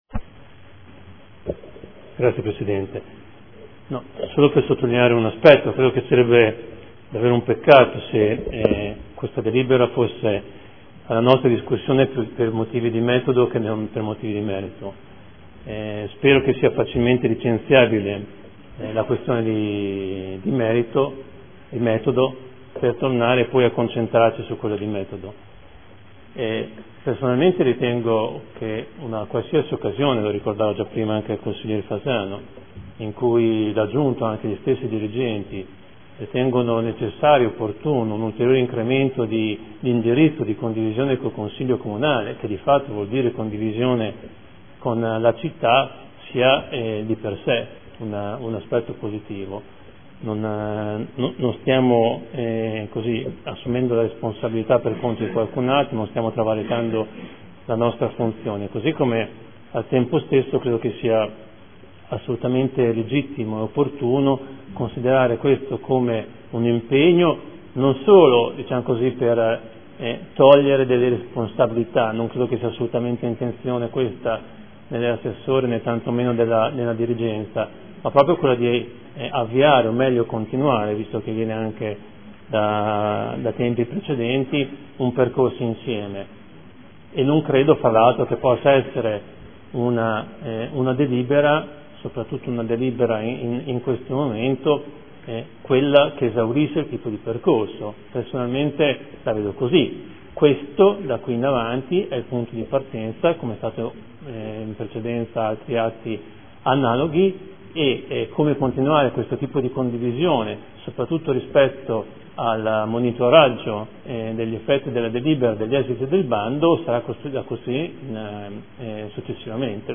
Fabio Poggi — Sito Audio Consiglio Comunale